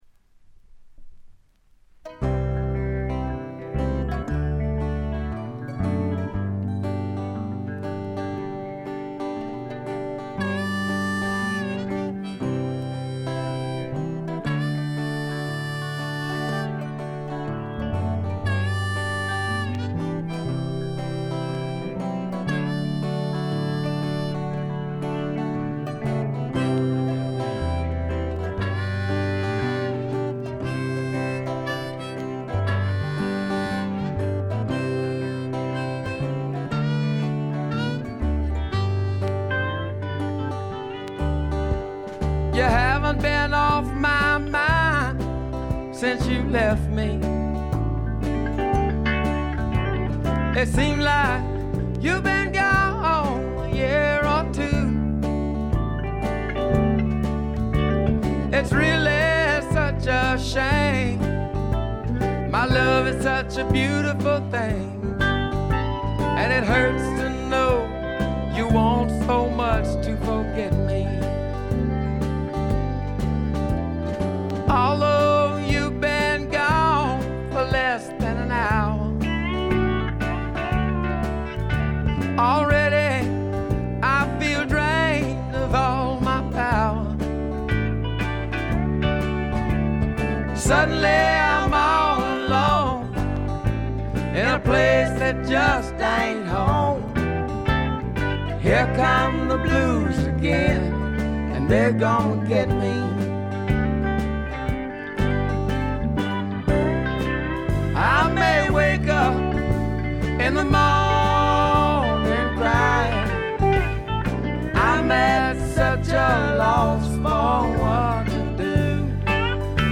静音部での微細なバックグラウンドノイズ、散発的なプツ音軽いものが2回ほど。
びしっと決まった硬派なスワンプ・ロックを聴かせます。
試聴曲は現品からの取り込み音源です。
Recorded at Paramount Recording Studio.